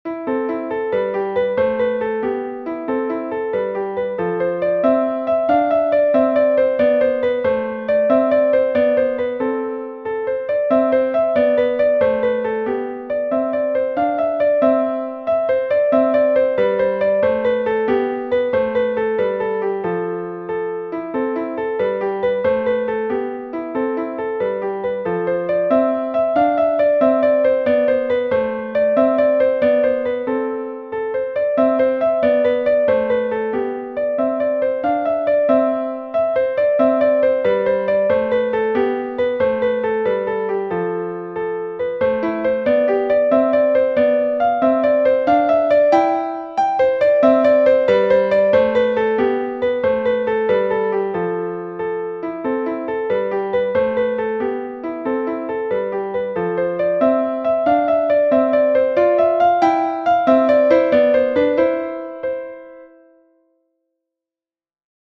easy and engaging piano solos
Instructional, Medieval and Renaissance